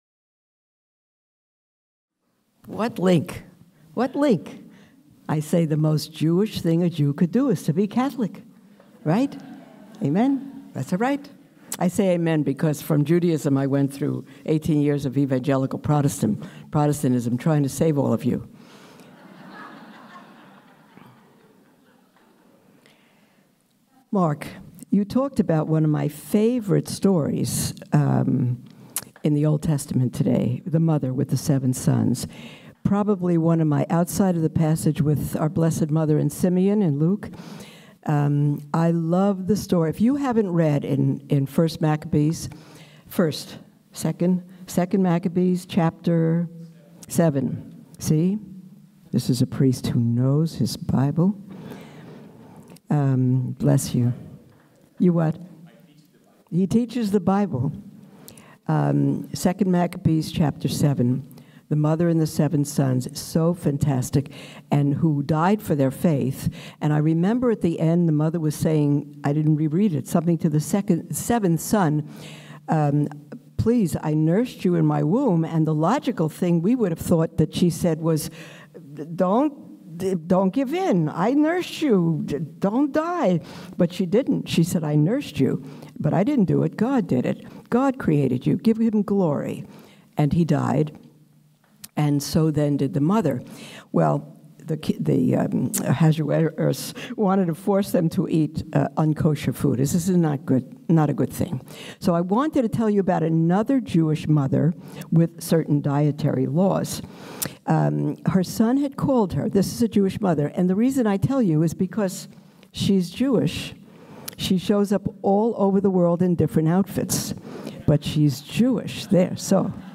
the final talk at the conference